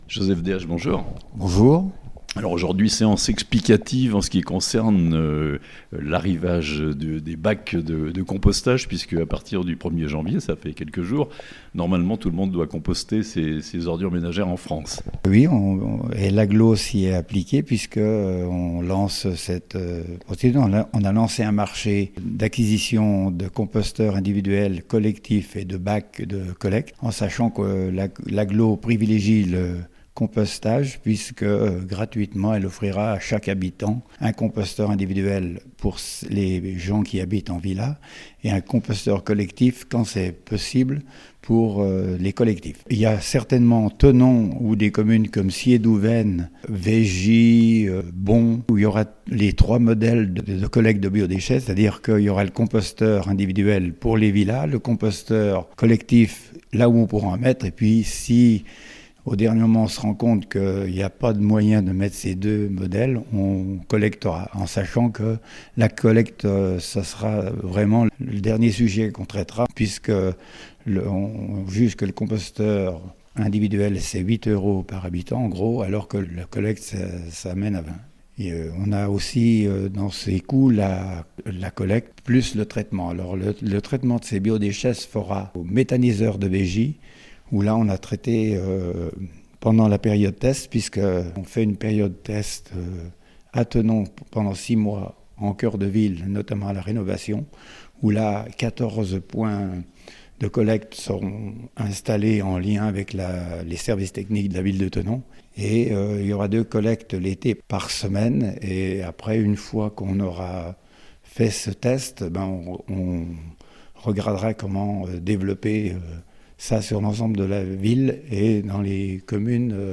Comme partout en France, le compostage des déchets alimentaires est désormais obligatoire dans l'agglomération de Thonon (interviews)